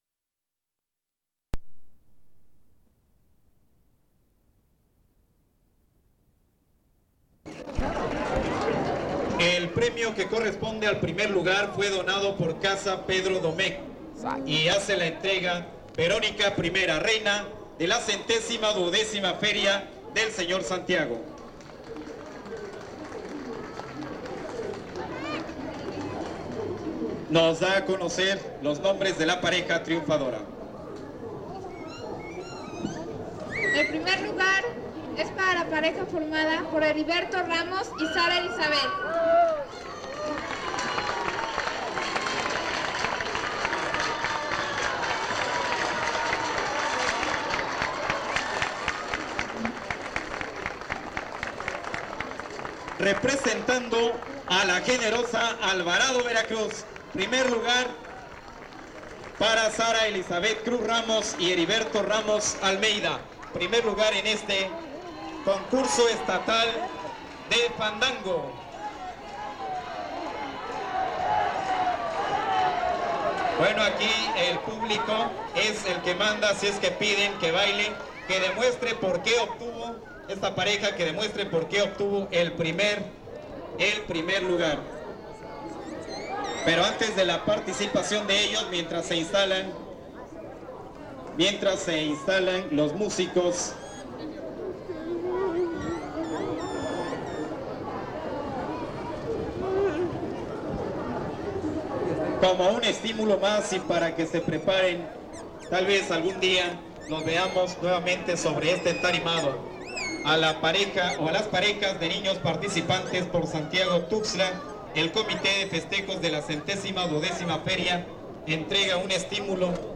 01 Premiación del Concurso Estatal de Fandango
Fiesta del Señor Santiago